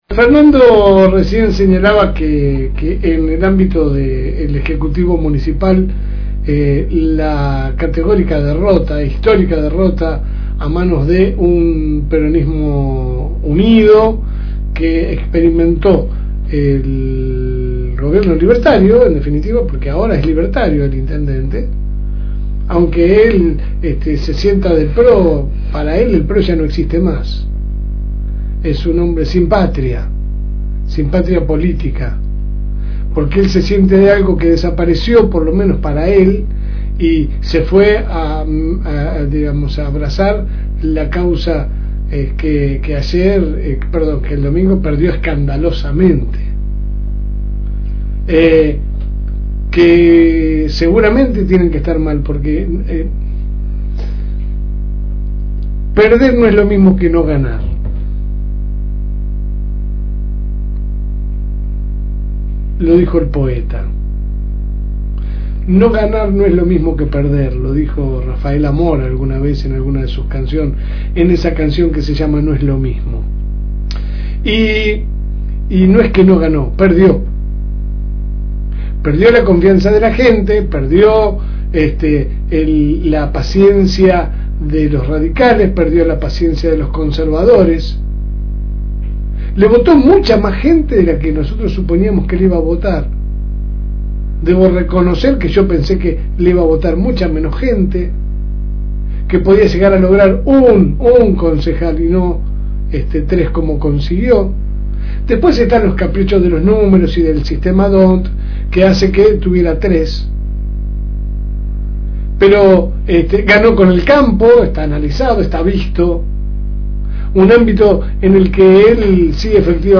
9-SEPT-2025-LSM-EDITORIAL.mp3